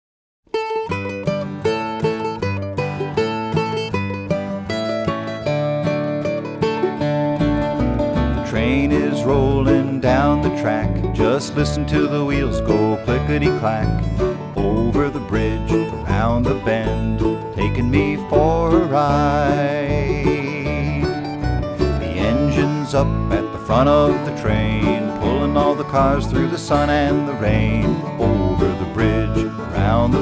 Home > Folk Songs
spirited versions of old and new folk songs